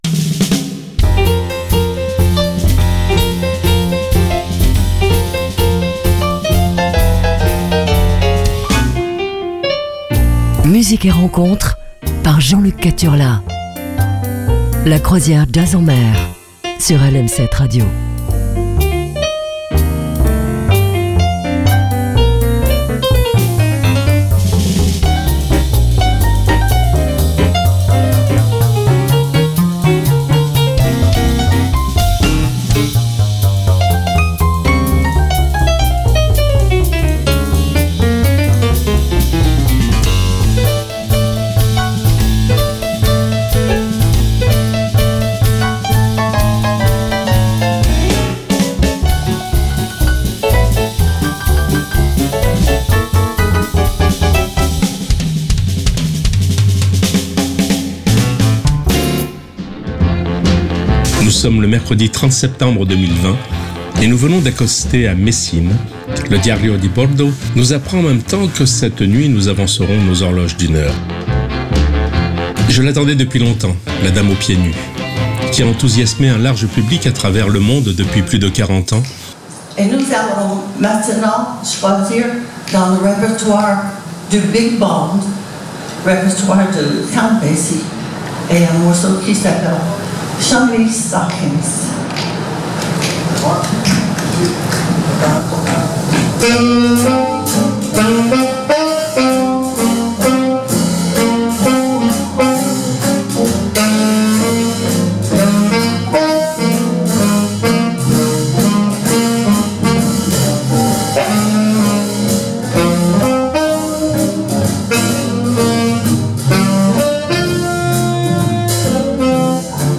rythmes jazzy